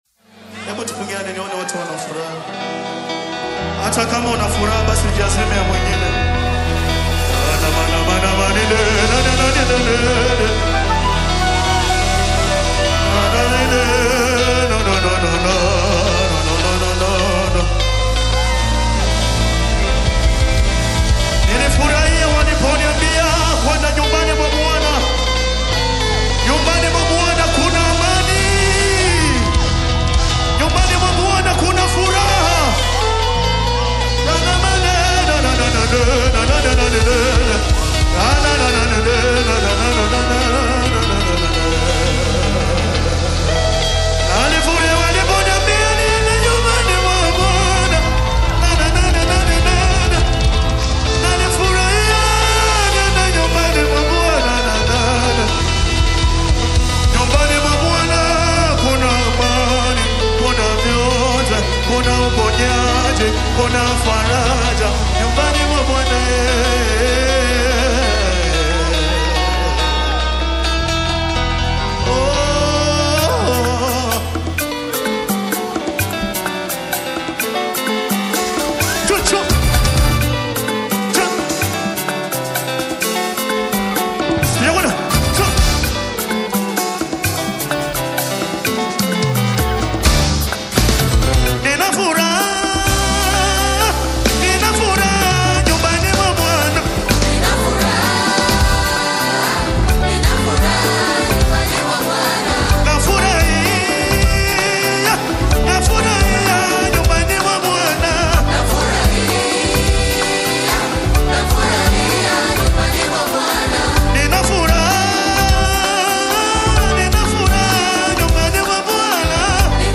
The rhythmically driven and vocally sparkling track